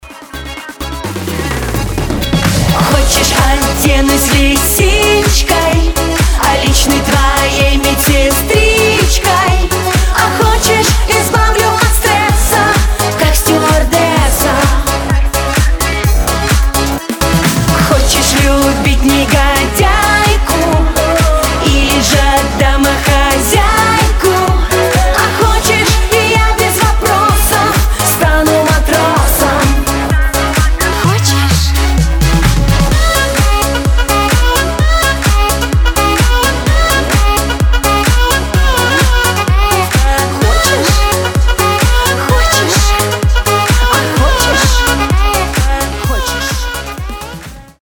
• Качество: 320, Stereo
поп
громкие
веселые